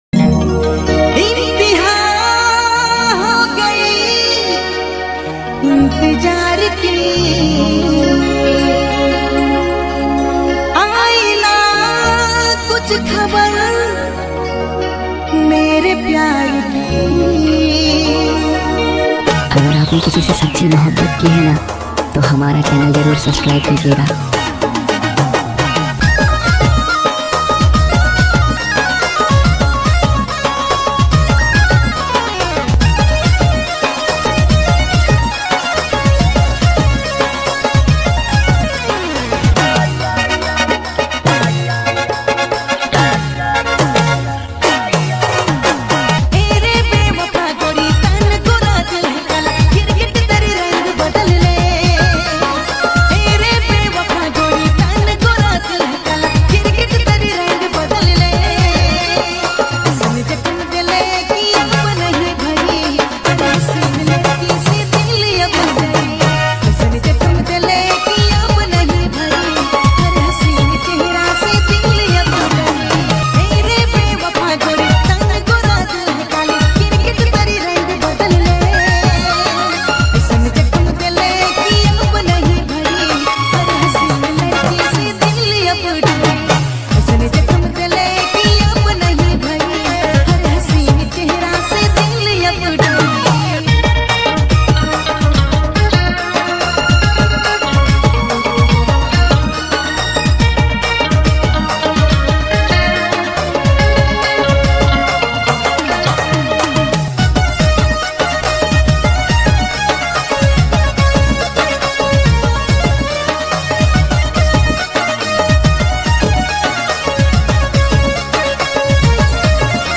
an energetic Nagpuri DJ remix